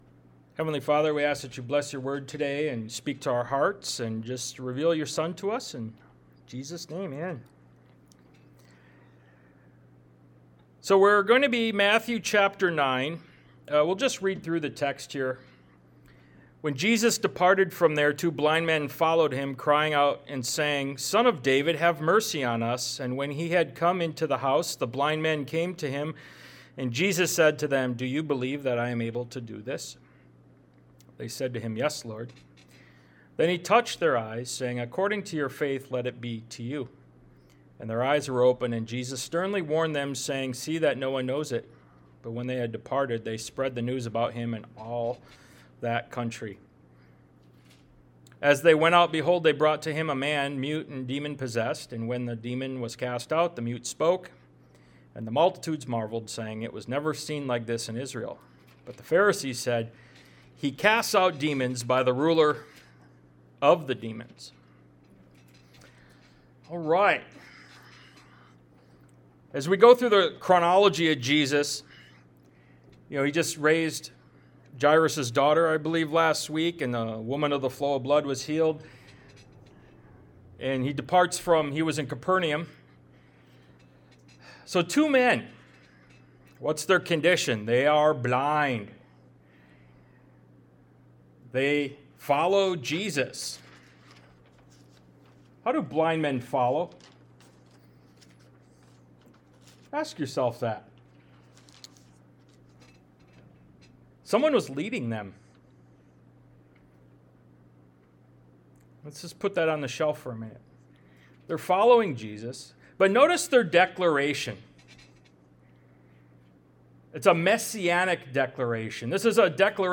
Ministry of Jesus Service Type: Sunday Morning « Are You Desperate?